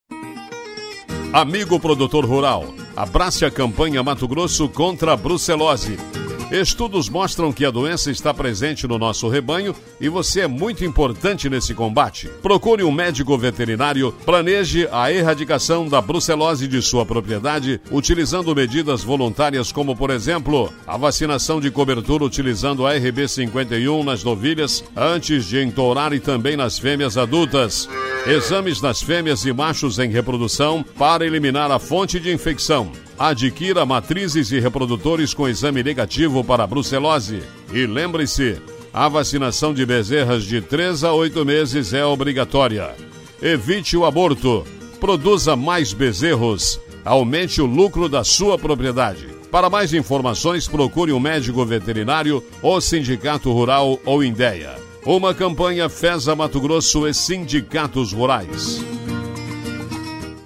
SPOT-2020-MT-Contra-a-Brucelose.mp3.mp3